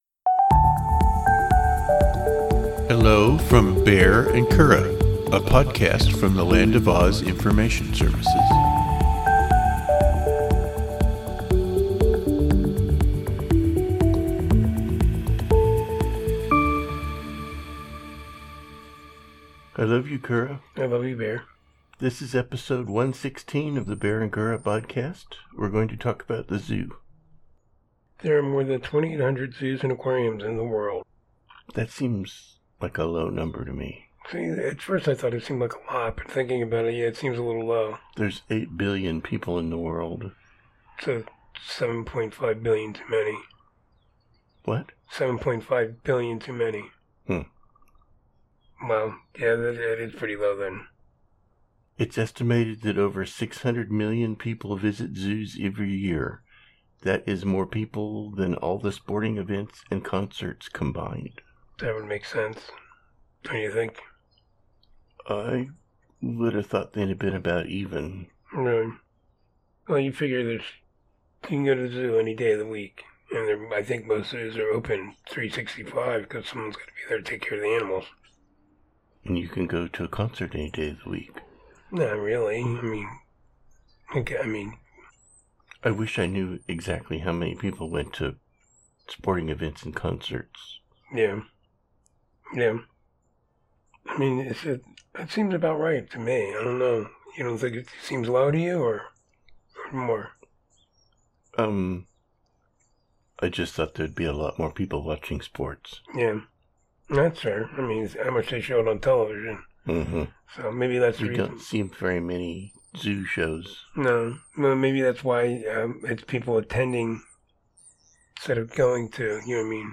Two married gay guys discuss life, synergy, and the pursuit of happiness.